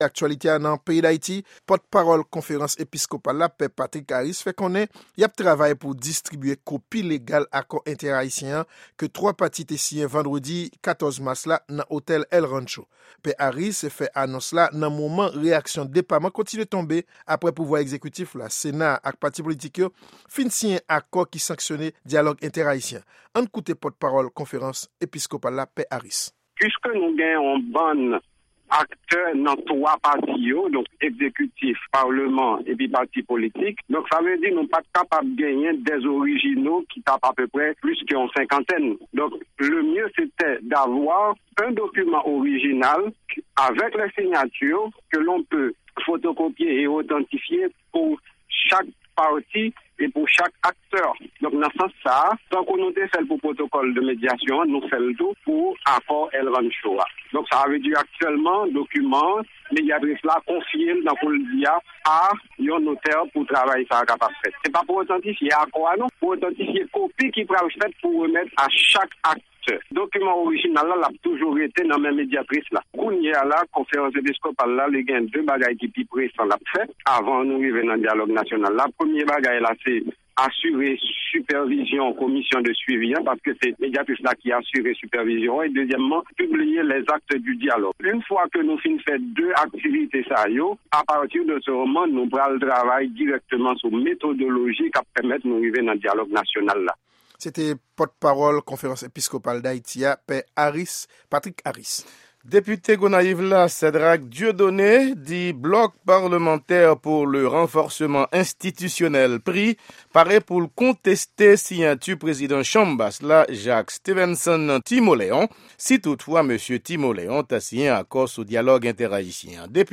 Repòtaj : Siyati Akò Entè-Ayisyen an - Dènye Nouvèl ak Reyaksyon